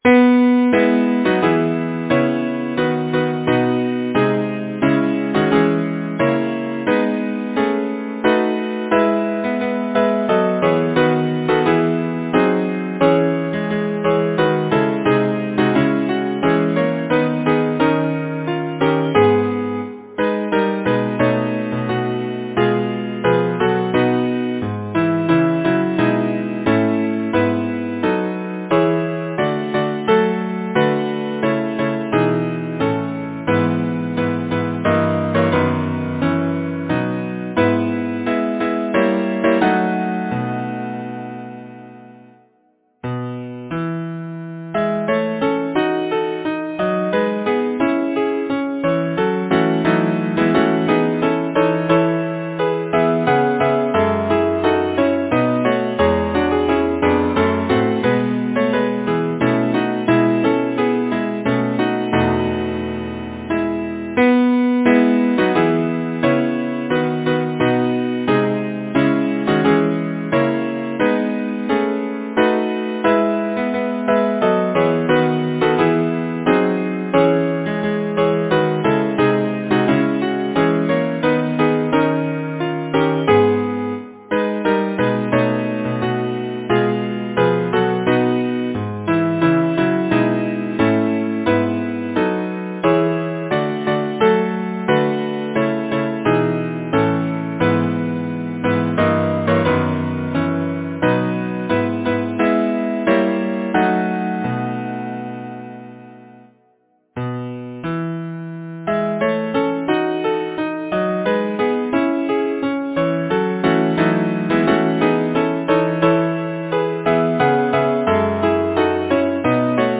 Title: Evening Composer: George John Bennett Lyricist: Edward Oxenford Number of voices: 4vv Voicing: SATB Genre: Secular, Partsong
Language: English Instruments: A cappella